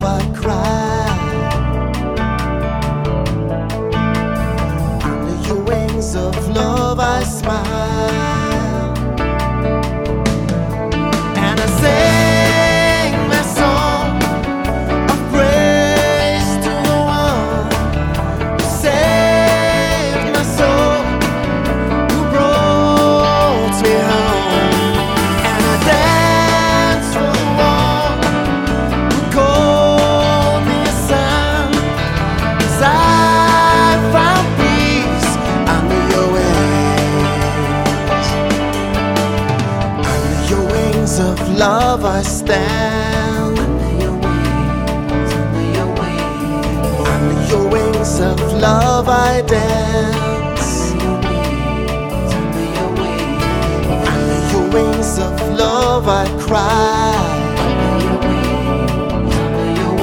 Gesang